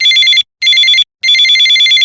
cellphone.ogg